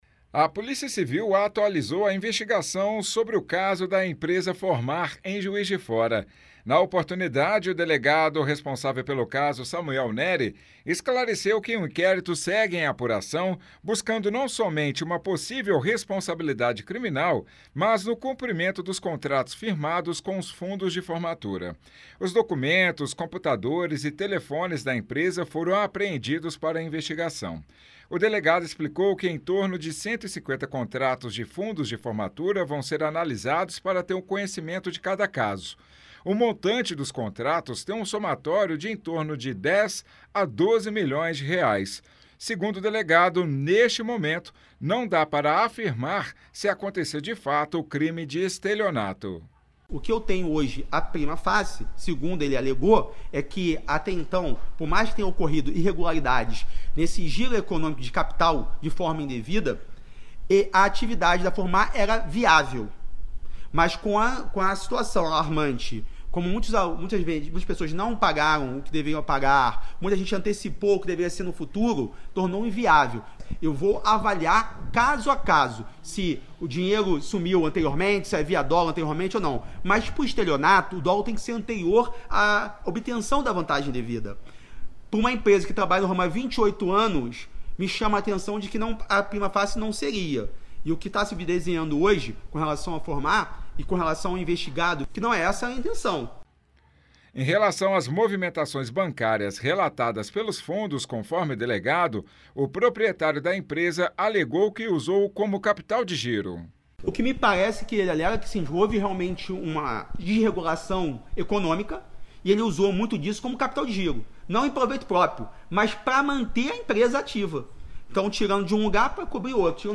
Na coletiva foi anunciado que os contratos pendentes serão cumpridos porque o Grupo Vision, de Montes Claros, firmou parceria com a Phormar e vai arcar com os custos.